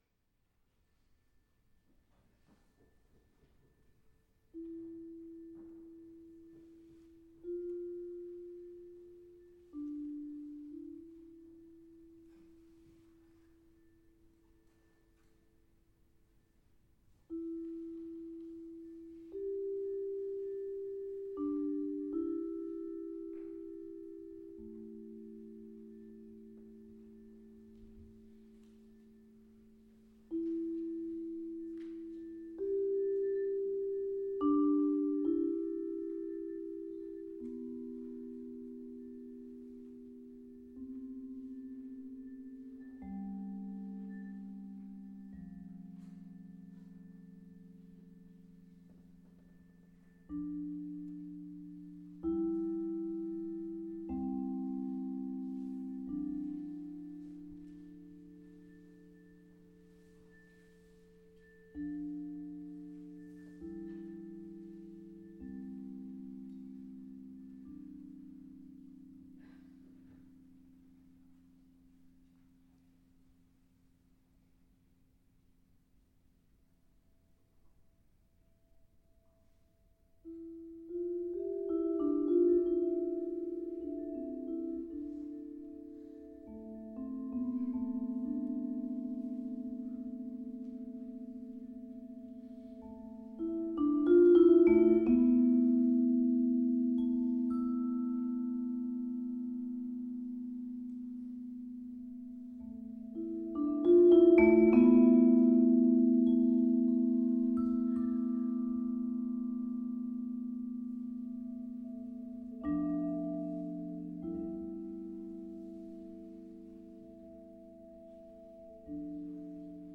slagwerk) – voorjaarseditie 2019.